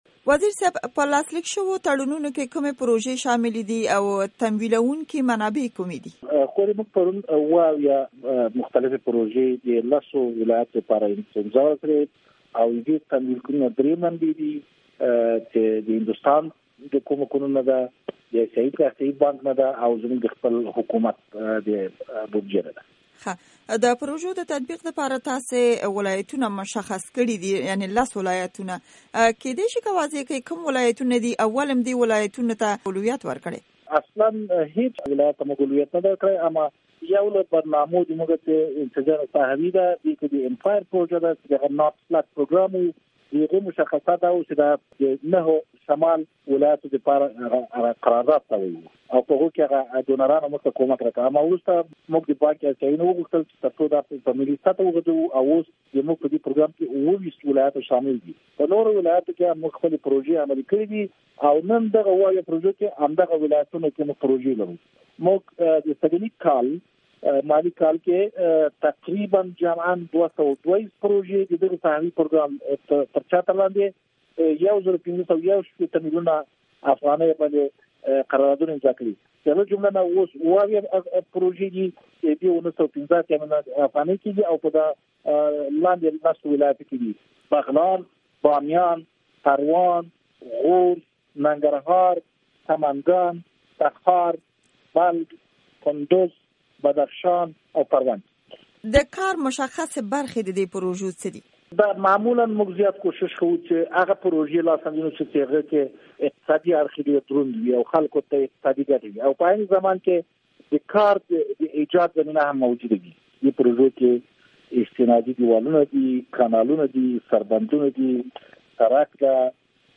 د کللیو او پراخیتا د وزیر سره بشپړه مرکه